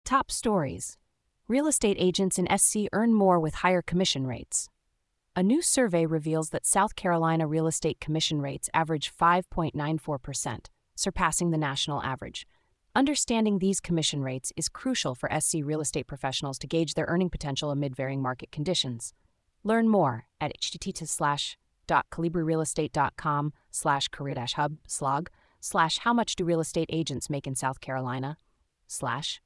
Daily briefing for real estate professionals in South Carolina